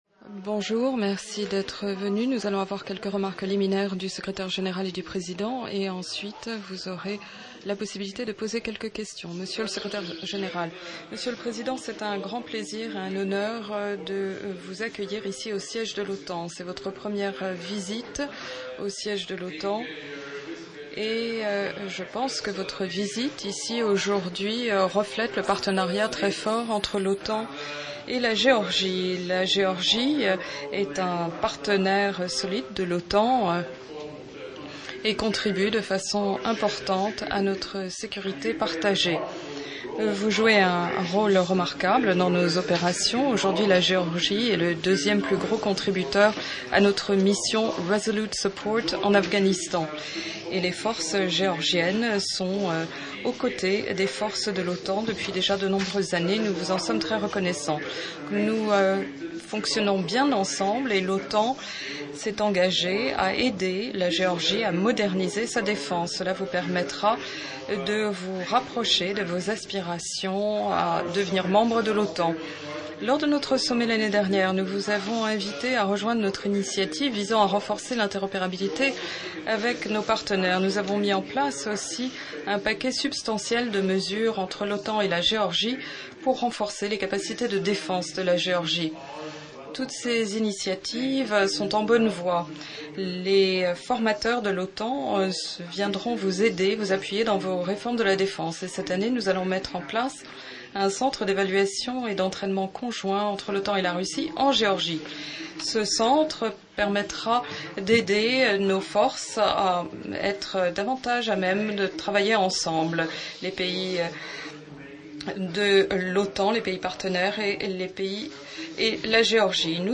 Joint press conference by NATO Secretary General Jens Stoltenberg and President Margvelashvili of Georgia